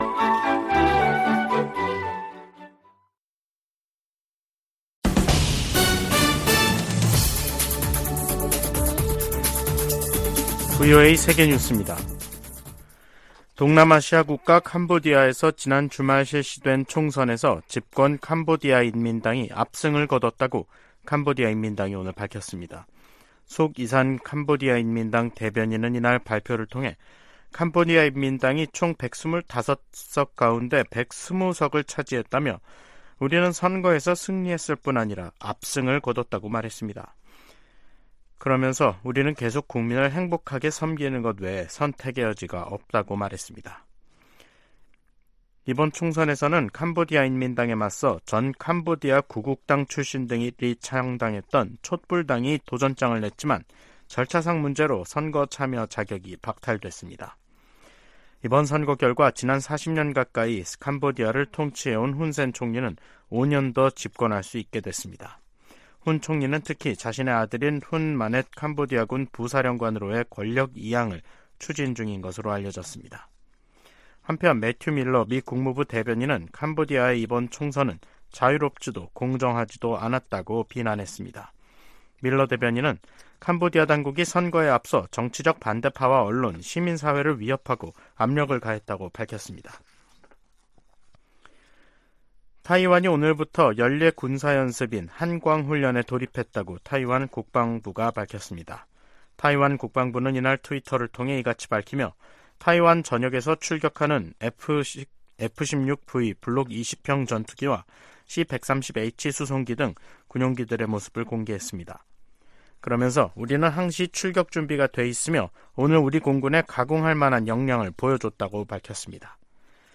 VOA 한국어 간판 뉴스 프로그램 '뉴스 투데이', 2023년 7월 24일 2부 방송입니다. 북한은 지난 19일 동해상으로 단거리 탄도미사일(SRBM) 2발을 발사한데 이어 사흘 만에 다시 서해상으로 순항미사일 수 발을 발사했습니다. 미국은 전략핵잠수함의 부산 기항이 핵무기 사용 조건에 해당된다는 북한의 위협을 가볍게 여기지 않는다고 백악관이 강조했습니다. 유엔군사령부는 월북한 주한미군의 신병과 관련해 북한과의 대화를 시작했다고 공식 확인했습니다.